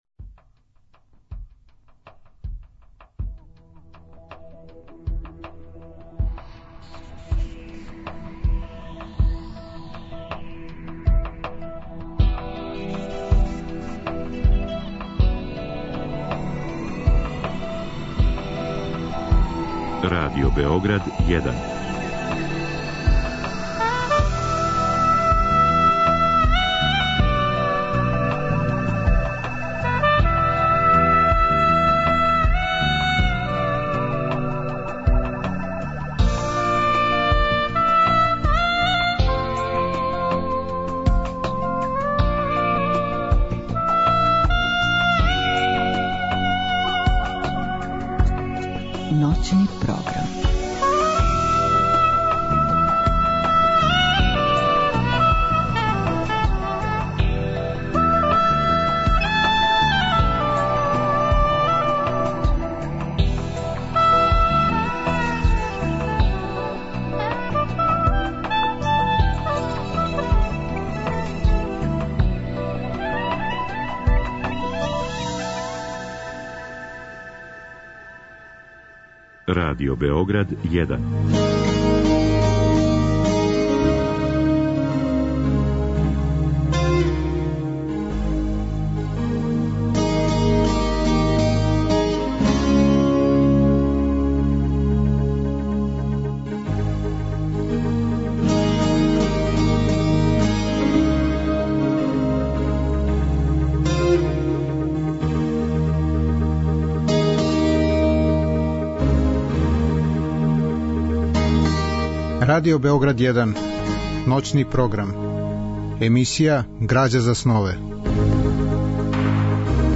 Разговор и добра музика требало би да кроз ову емисију и сами постану грађа за снове.
У другом делу емисије, од два до четири часa ујутро, слушаћемо одабране делове радио-драме Људи говоре која је рађена по истоименом прозном делу Растка Петровића.